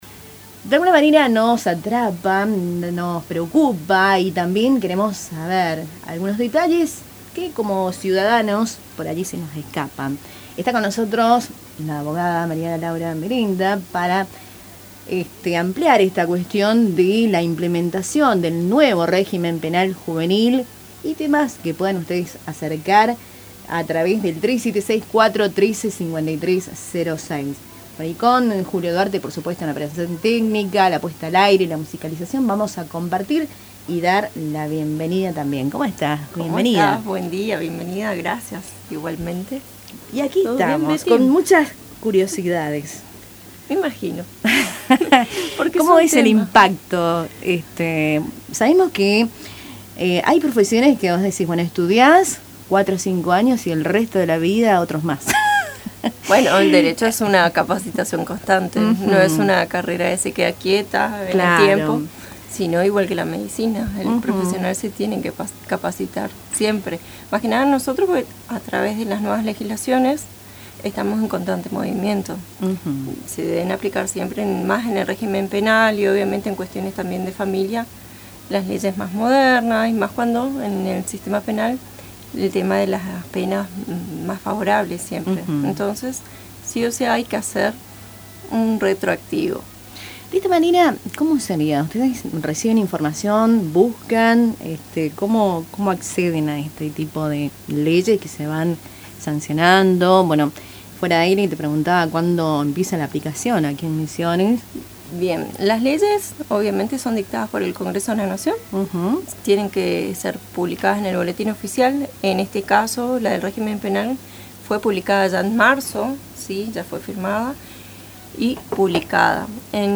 Durante la entrevista, la letrada explicó que la ley ya fue sancionada y publicada en el Boletín Oficial en marzo, pero su aplicación en las provincias requiere un período de adecuación. En el caso de Misiones, este proceso demandará seis meses, principalmente para la capacitación de jueces, fiscales, defensores y equipos interdisciplinarios, además de la asignación de recursos presupuestarios.